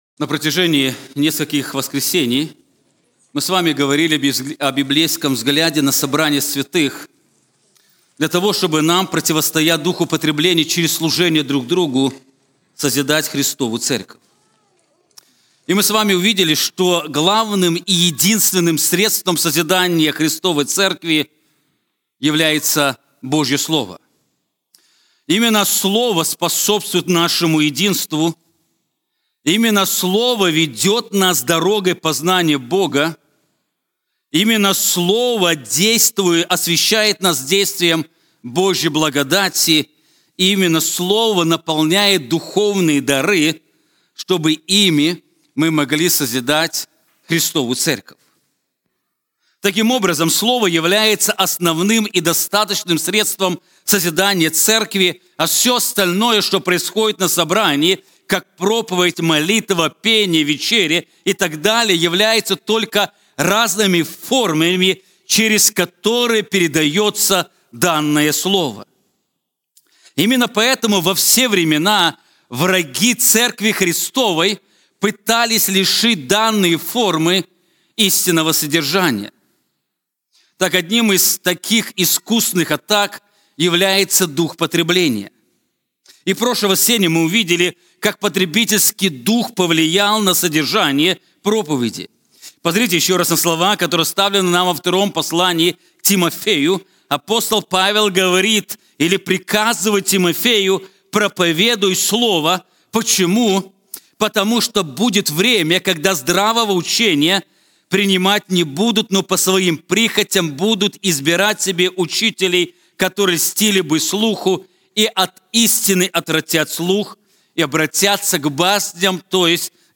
Sermons – Word Of Truth Bible Church podcast